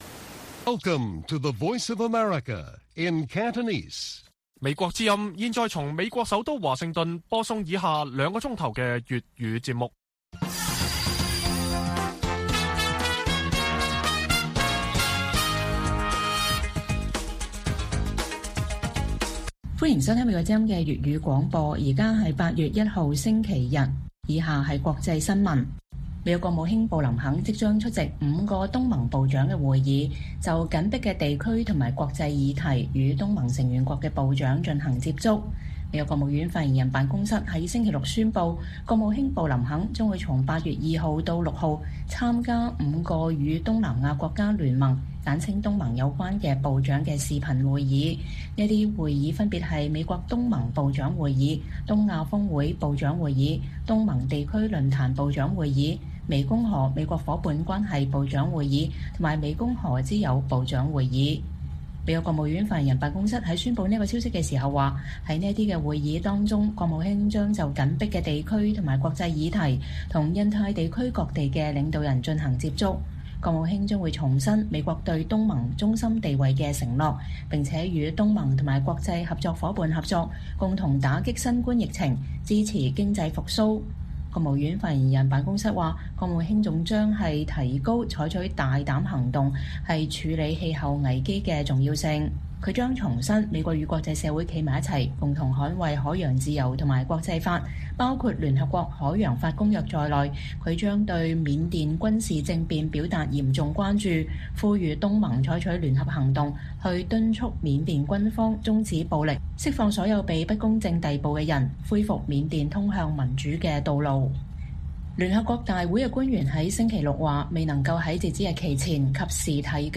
粵語新聞 晚上9-10點: 布林肯即將出席5個東盟部長會議
北京時間每晚9－10點 (1300-1400 UTC)粵語廣播節目布林肯即將出席5個東盟部長會議